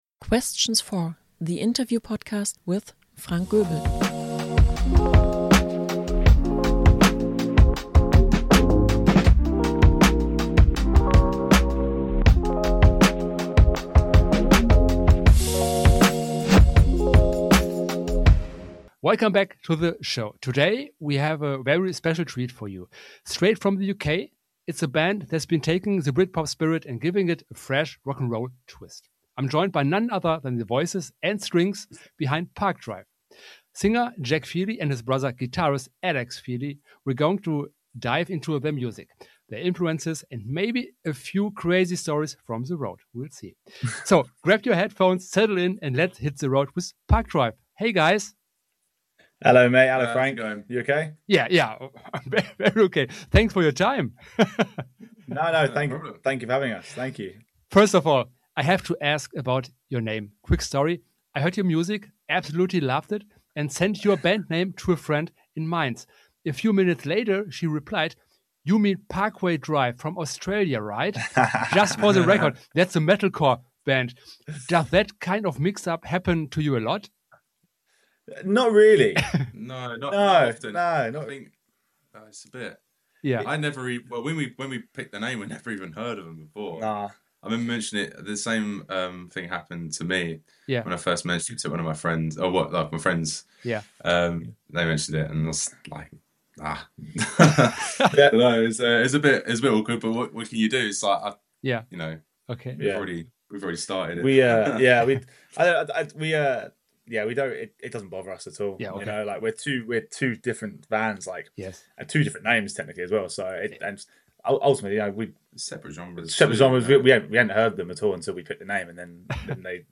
It’s a conversation about nostalgia, creativity, and the enduring spirit of rock’n’roll — with plenty of laughs along the way.